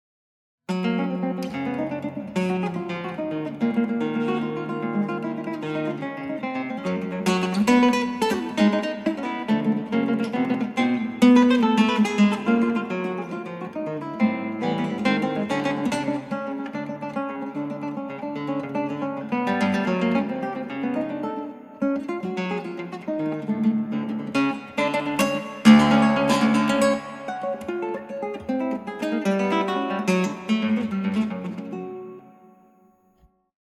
クラシックギター　ストリーミング　コンサート
３番目の変奏曲・・・現代曲バリバリでつ。
この曲の難しさは変化自在な音色の変化と、レンジ幅のあるディナーミクの表現でしょうか。